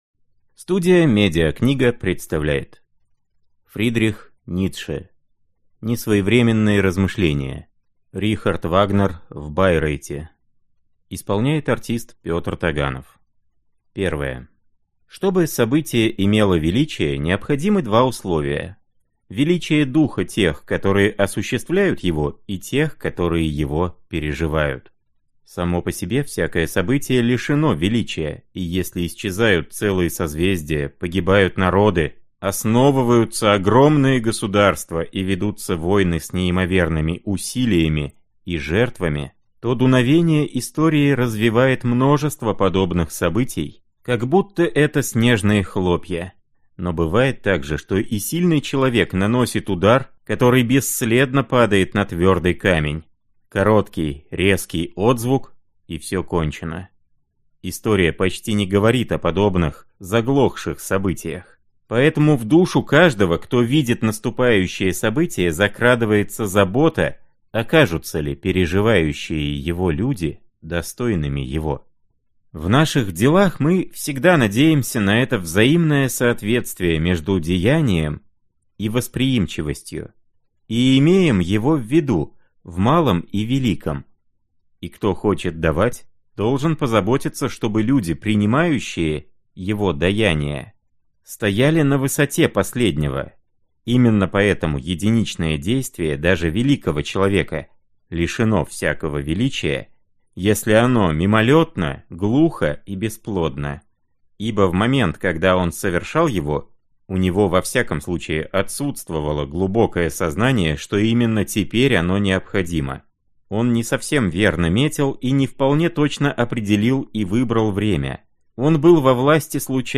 Аудиокнига Рихард Вагнер в Байрейте | Библиотека аудиокниг